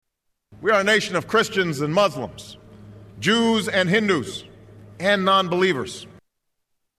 Tags: Famous Inaguration clips Inaguration Inaguration speech President Obama